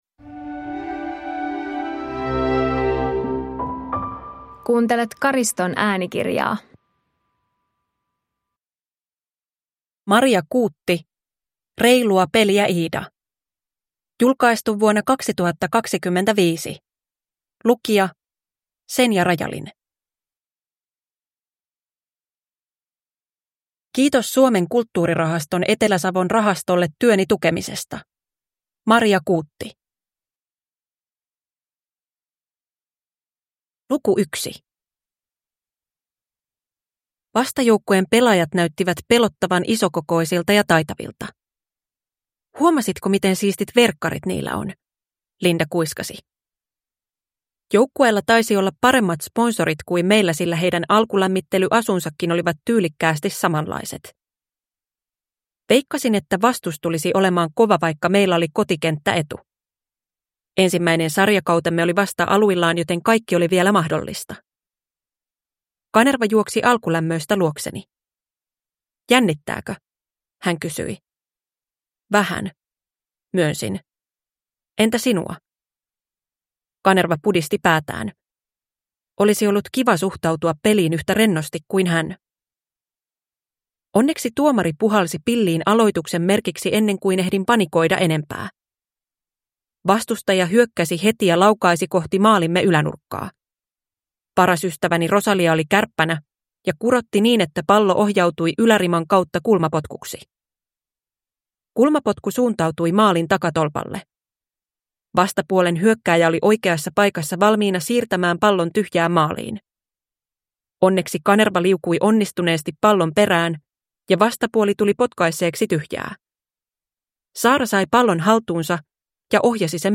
Reilua peliä, Iida! – Ljudbok